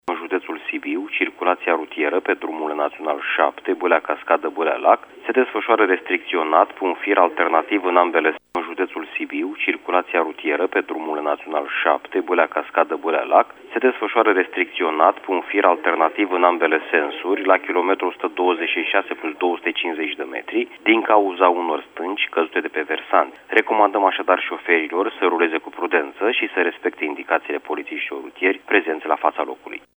Comisarul șef  de Poliție